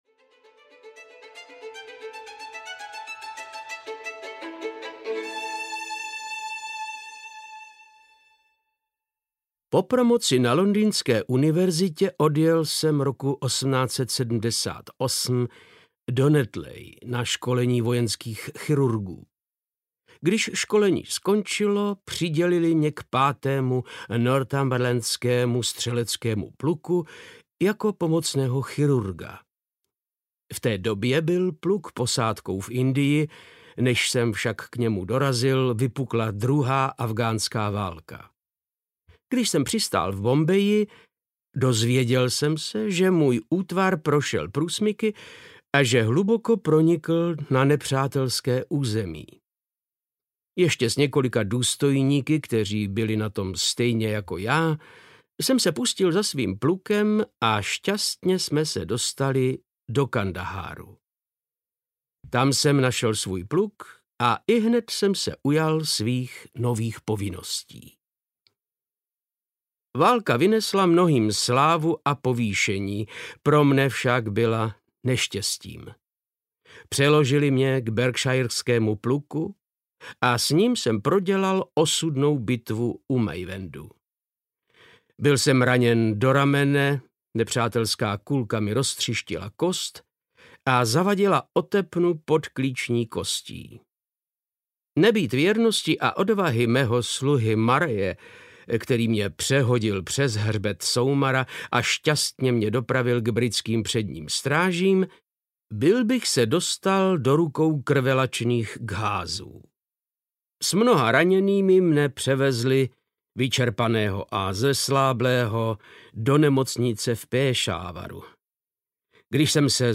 Studie v šarlatové audiokniha
Ukázka z knihy
• InterpretVáclav Knop